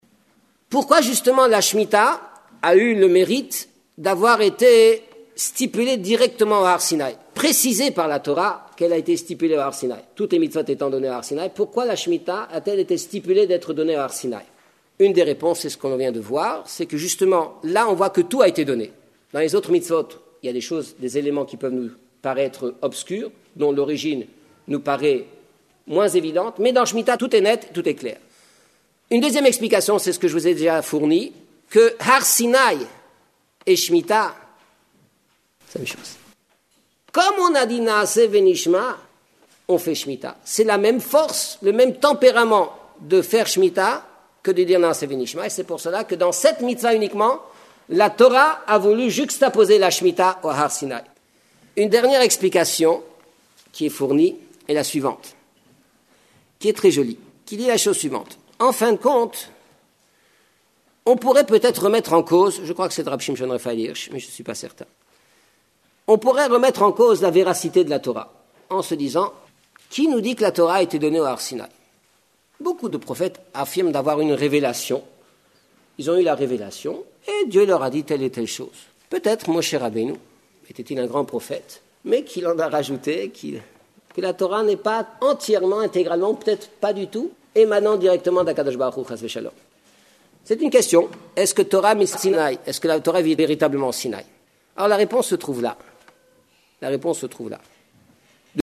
Elle a été donnée à la synagogue de la Adass Yeréïm rue Cadet à Paris, conjointement organisée avec le Igoud ‘Harédi, le Motsé Sabbath, à la sortie du Shabbath Mishpatim 25 Shevath 5794 – 5 février 1994 .